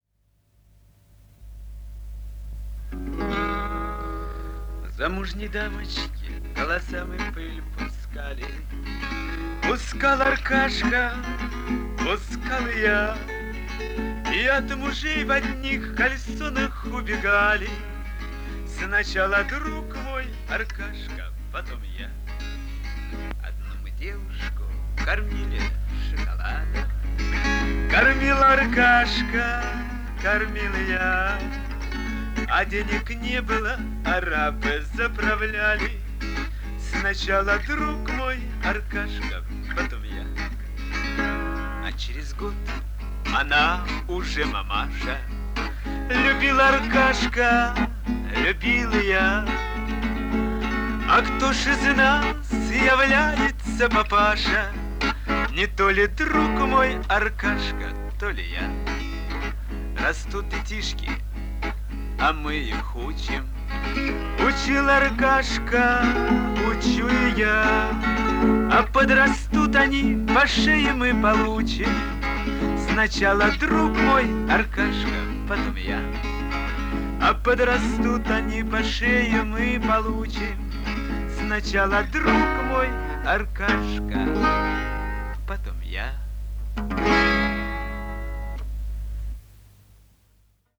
Пара дворовых варианта